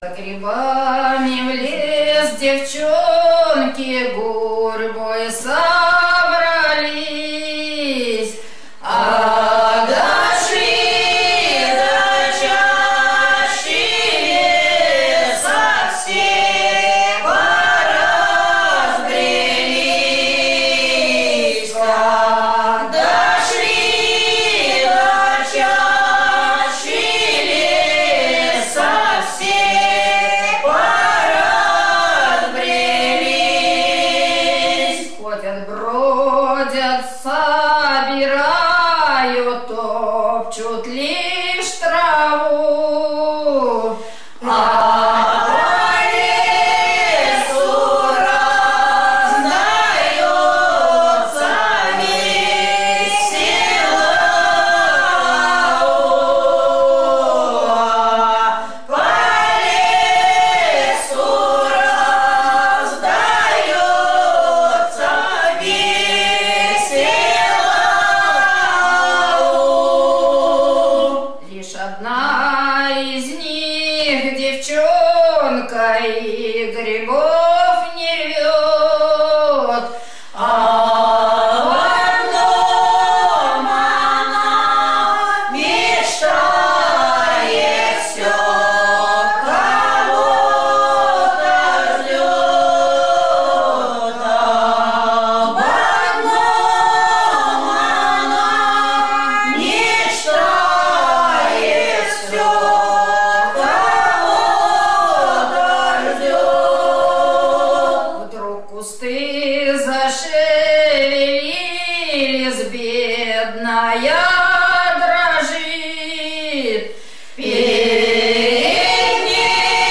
We'll start with a Russian folk song from the  early 20th century written in the genre of the heartbreaking City Romance, Za Gribami v L'es Devchata (For Mushrooms into the Forest...).
Here is a fantastic recording form 2008 from near Ulyanovsk, Central Russia: